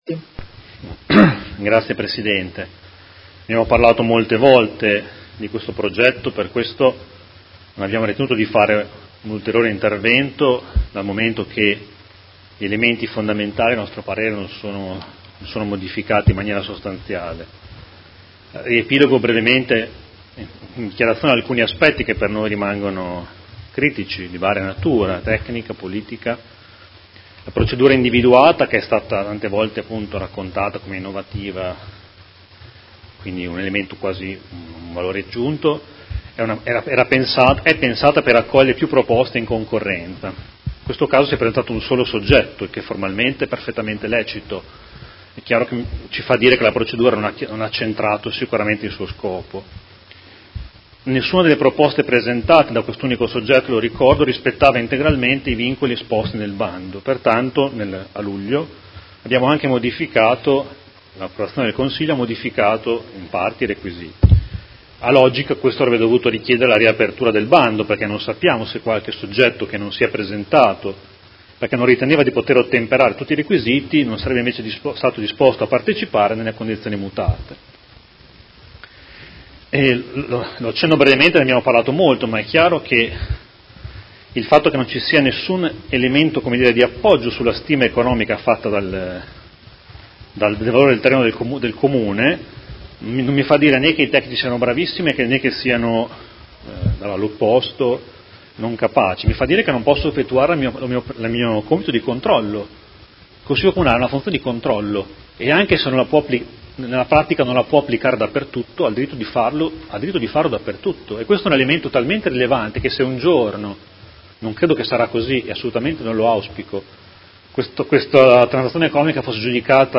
Mario Bussetti — Sito Audio Consiglio Comunale
Seduta del 17/12/2018 Dichiarazione di voto.